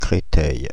Créteil (French pronunciation: [kʁetɛj]
Fr-Paris--Créteil.ogg.mp3